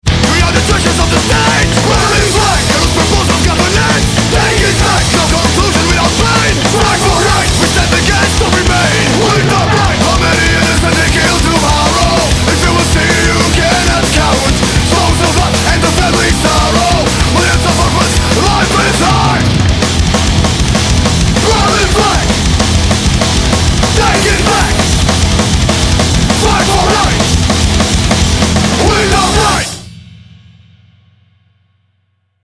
Vokálně instrumentální skupina